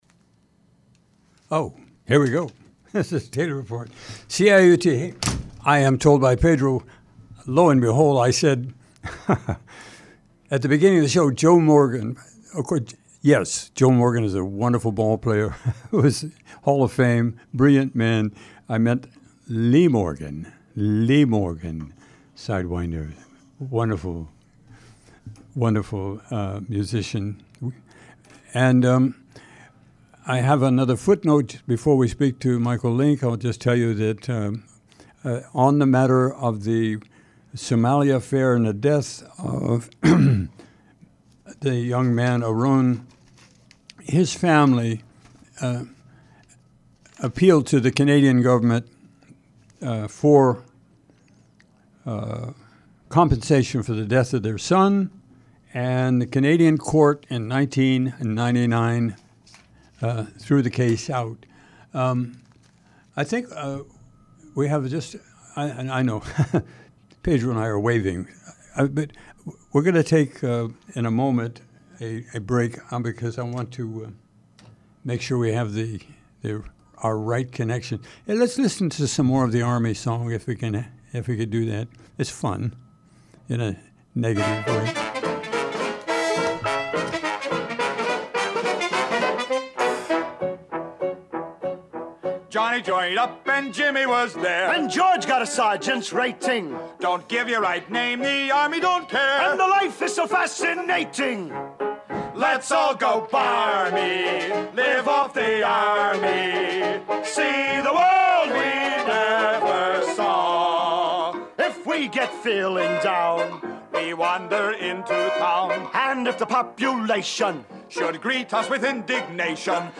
Location Recorded: Toronto, Ontario